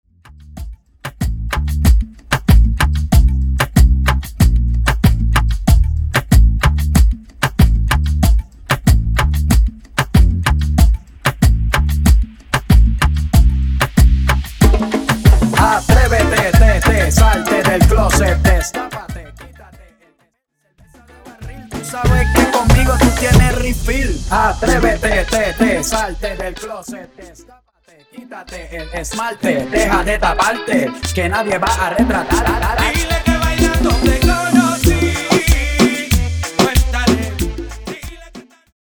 Mashup Dirty